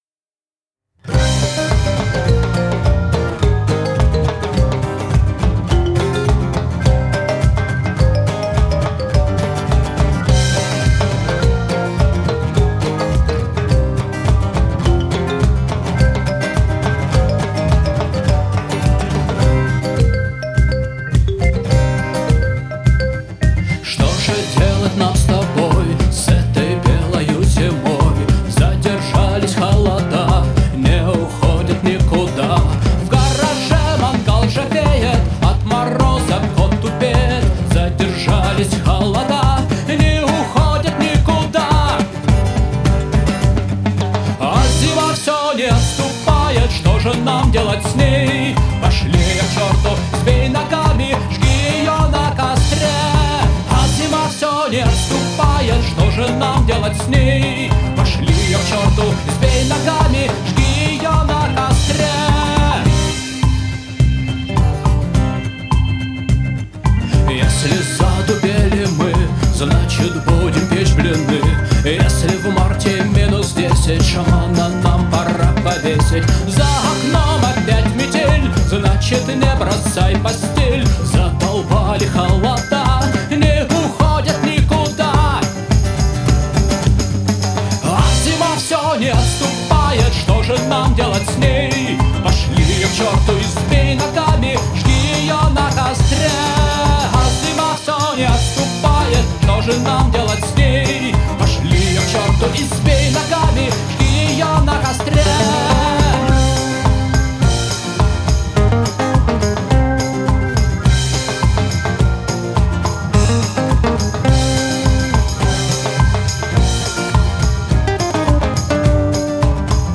Авторская песня с элементами латины. Зима (Масленица)